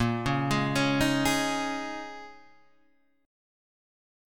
A#m11 chord